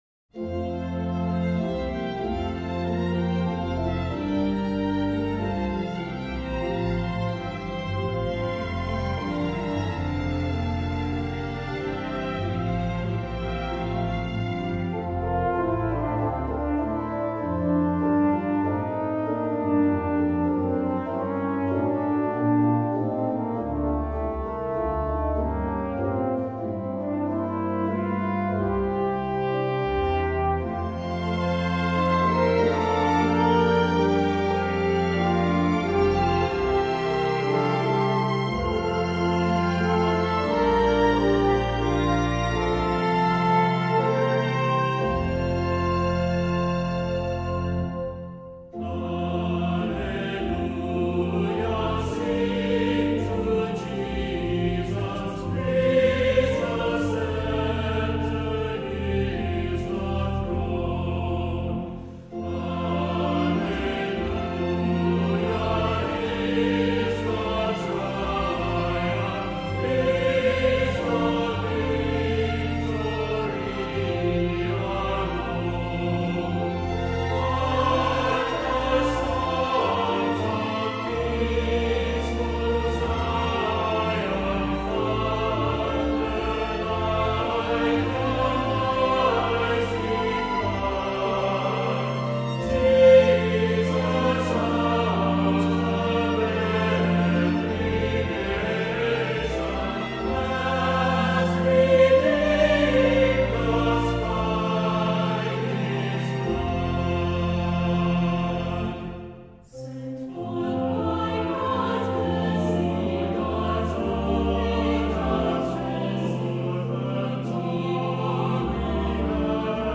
Voicing: Assembly,SAB